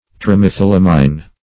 Search Result for " trimethylamine" : The Collaborative International Dictionary of English v.0.48: Trimethylamine \Tri*meth`yl*am"ine\, n. [Trimethyl- + amine.]
trimethylamine.mp3